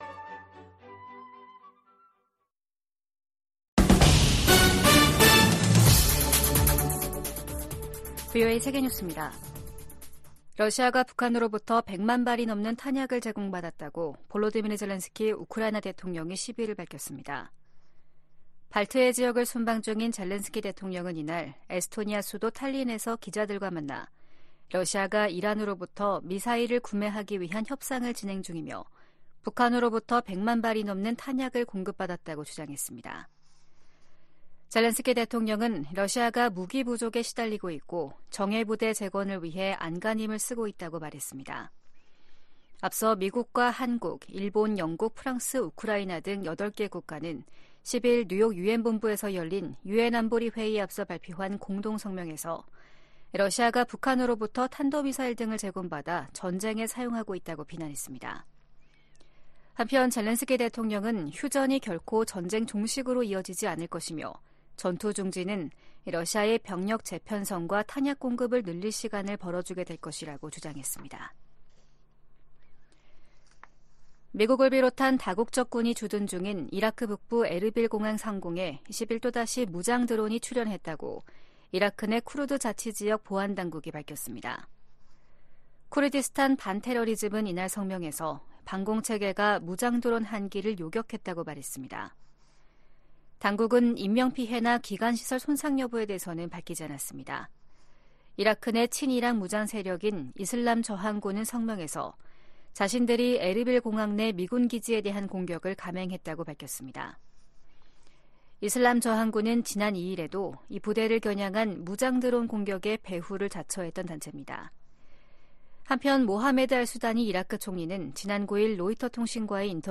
VOA 한국어 아침 뉴스 프로그램 '워싱턴 뉴스 광장' 2024년 1월 12일 방송입니다. 백악관은 팔레스타인 무장정파 하마스가 북한 무기를 사용한 사실을 인지하고 있다고 밝혔습니다. 미국, 한국, 일본 등이 유엔 안보리 회의에서 러시아가 북한에서 조달한 미사일로 우크라이나를 공격하고 있는 것을 강력하게 비판했습니다. 미국은 중국과의 올해 첫 국방 정책 회담에서 북한의 최근 도발에 우려를 표명하고 철통 같은 인도태평양 방위 공약을 재확인했습니다.